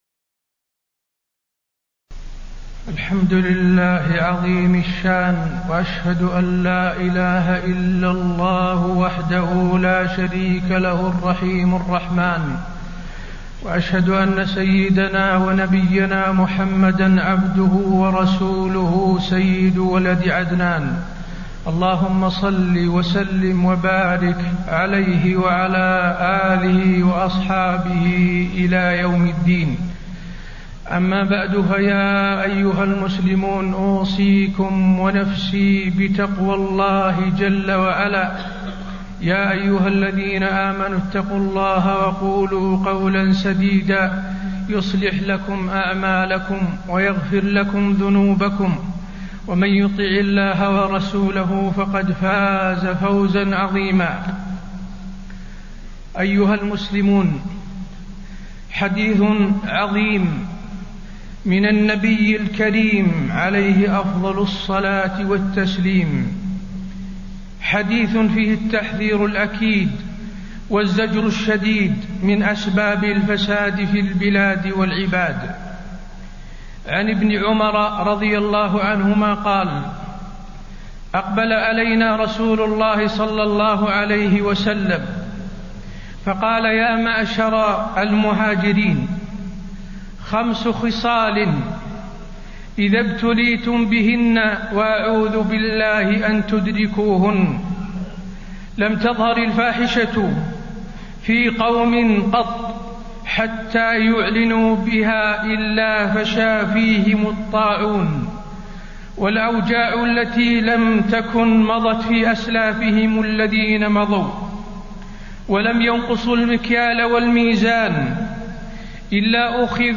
تاريخ النشر ٧ رجب ١٤٣٤ هـ المكان: المسجد النبوي الشيخ: فضيلة الشيخ د. حسين بن عبدالعزيز آل الشيخ فضيلة الشيخ د. حسين بن عبدالعزيز آل الشيخ وجوب تحكيم شرع الله